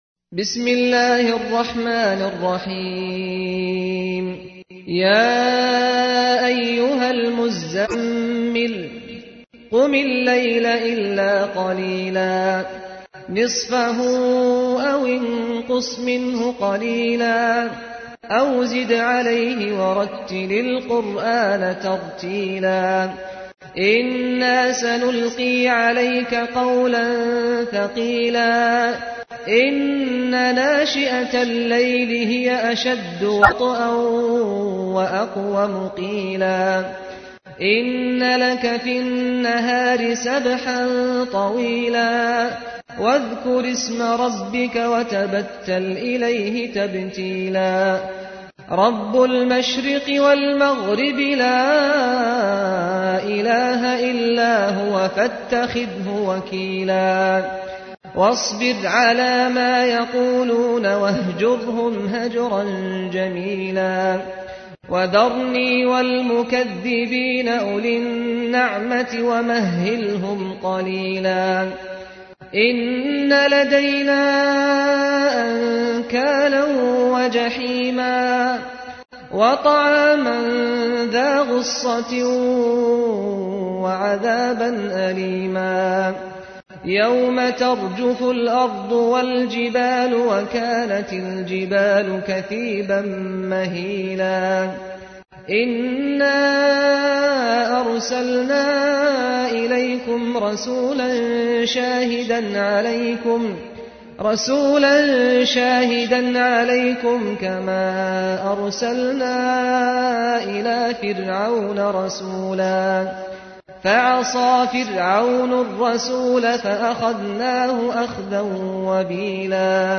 تحميل : 73. سورة المزمل / القارئ سعد الغامدي / القرآن الكريم / موقع يا حسين